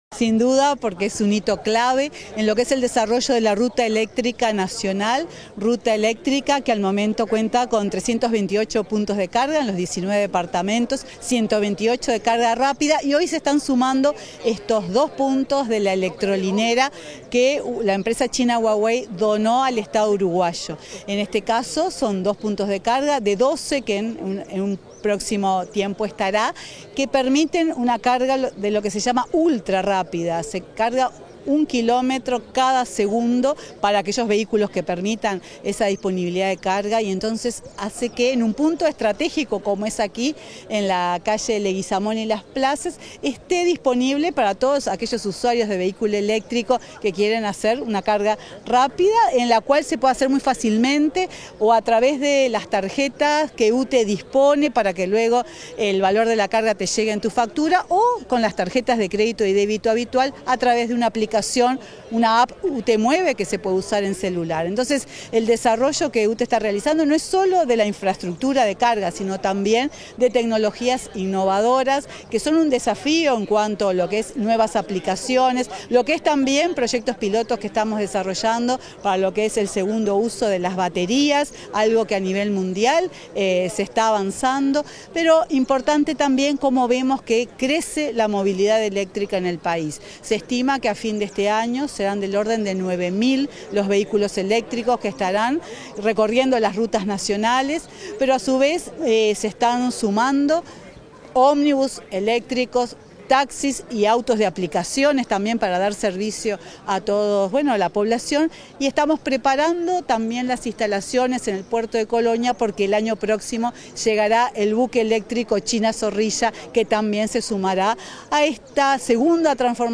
Declaraciones de la presidenta de UTE, Silvia Emaldi
Tras inaugurar la primera electrolinera del país, la presidenta de UTE, Silvia Emaldi fue entrevistada por los medios informativos presentes.